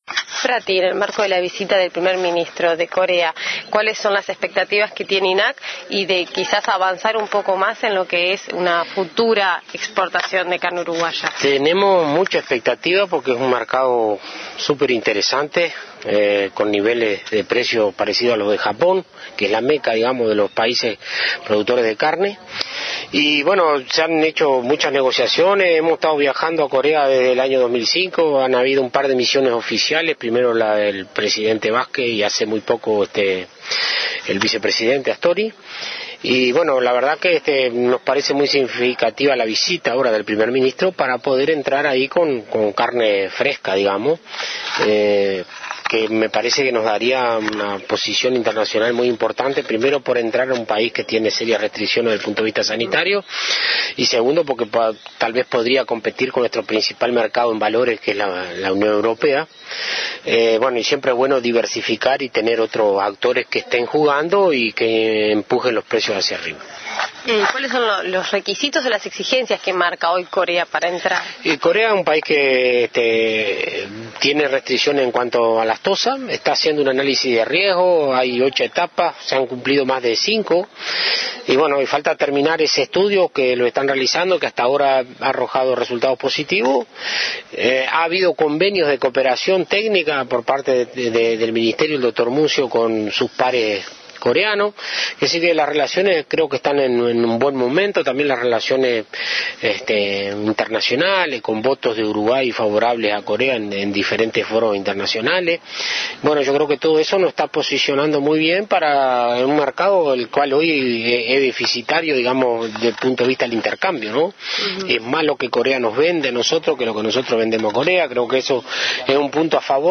Fratti - recepcion a ministro coreano 1.mp3